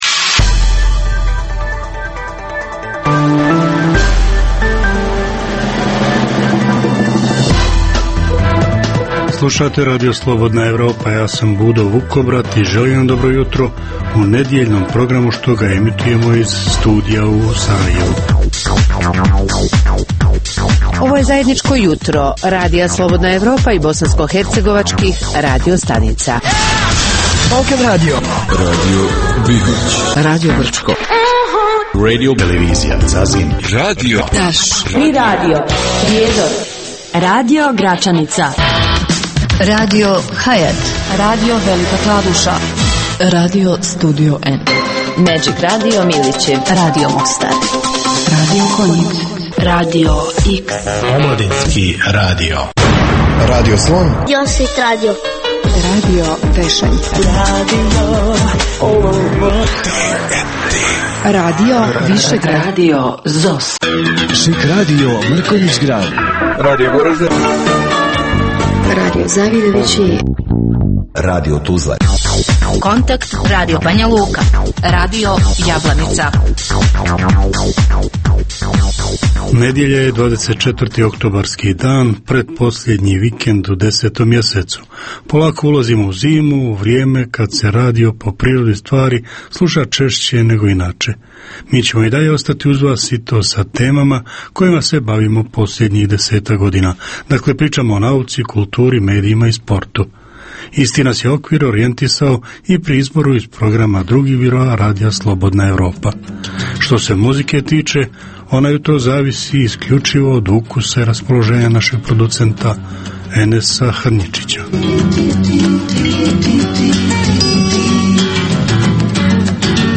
Jutarnji program namijenjen slušaocima u Bosni i Hercegovini.